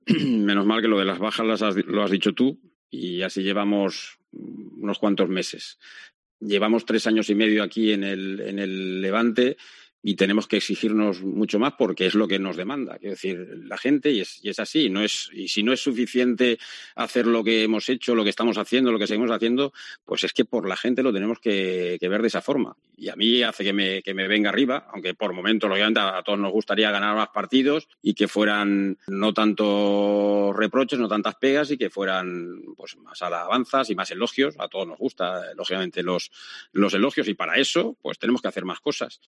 AUDIO. Paco López habla de críticas y elogios